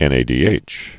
(ĕnā-dē-āch)